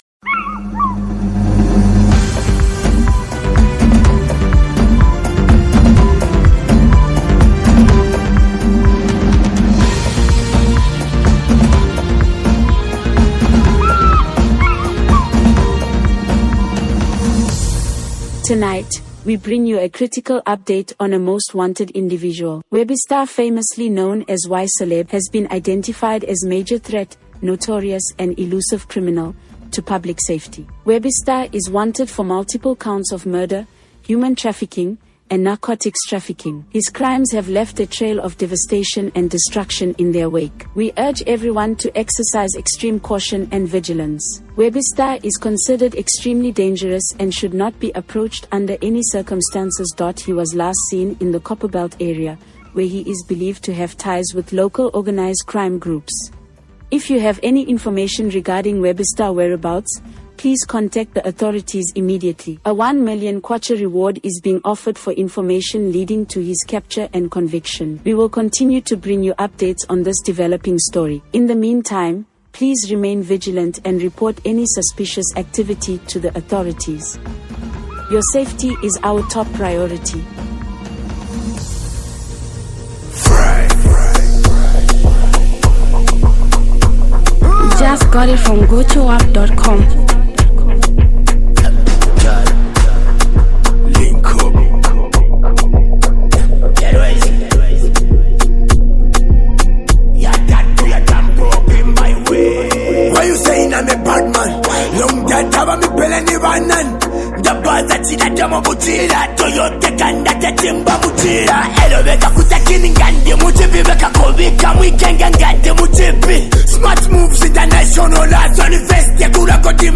the renowned Zambian rapper